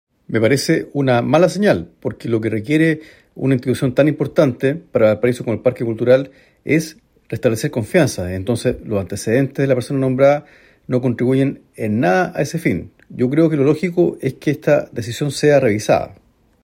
diputado-cuello.mp3